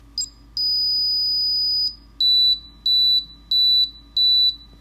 The sound is repeated over and over (with a few seconds pause in between) until I power it down.
powered on It beeps out what I interpret as a: dit brap dah dah dah